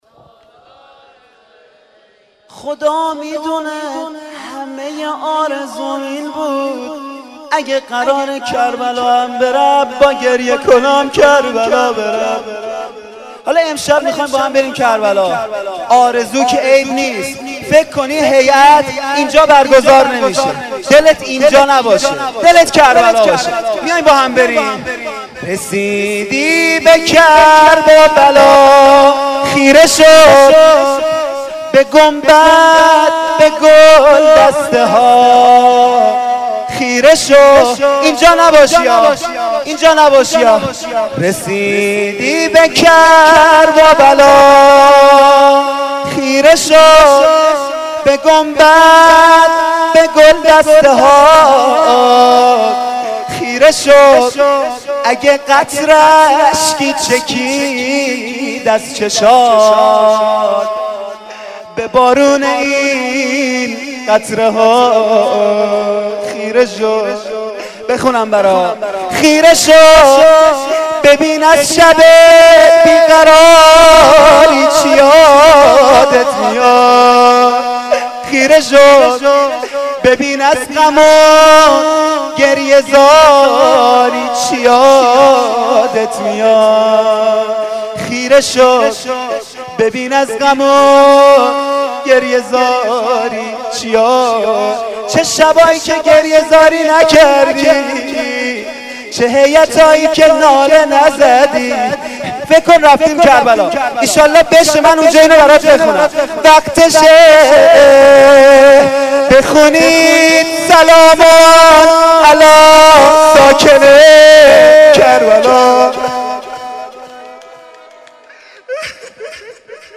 بخش-دوم-روضه.mp3